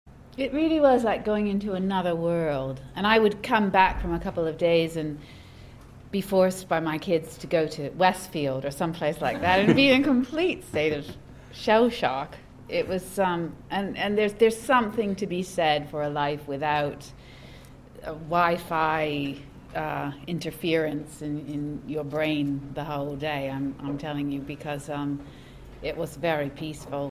Then small round table interviews with many of the actors involved.